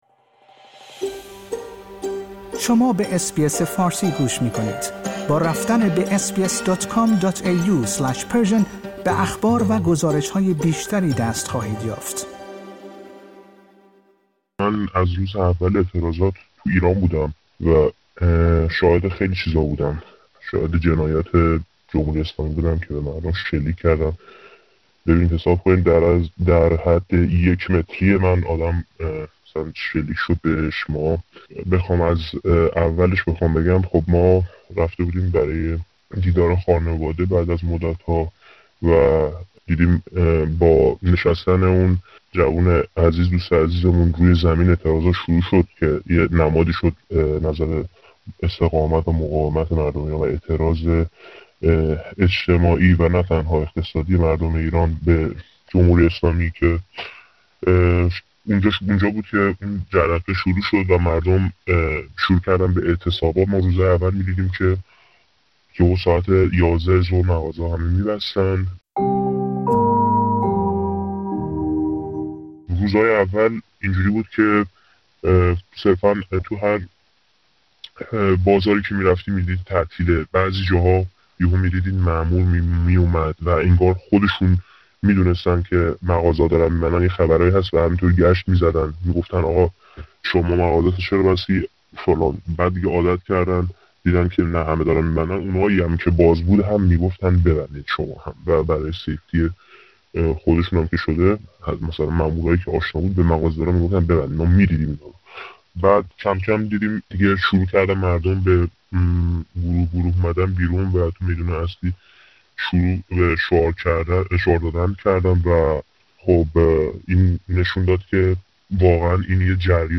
یک شاهد عینی، در گفت‌وگویی با اس‌بی‌اس فارسی آنچه در اعتراضات سه هفته اخیر در ایران دیده را شرح می‌دهد.
یک شاهد عینی که اخیرا از ایران به استرالیا برگشته است در این گفت‌وگو با اس‌بی‌اس فارسی روزهای حضور در اعتراضات در ایران و نحوه سرکوب معترضان را شرح داده است. برای حفظ امنیت و هویت فرد مذکور اس‌بی‌اس فارسی نام او را در این گزارش معرفی نمی‌کند و صدای او را تغییر داده است.